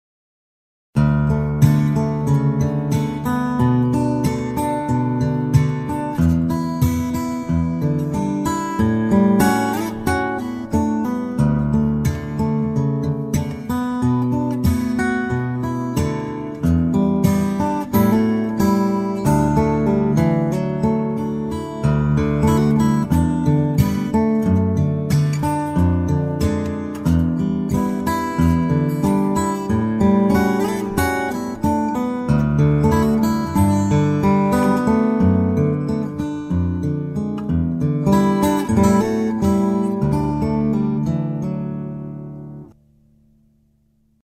solo guitar arrangement